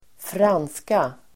Uttal: [²fr'an:ska]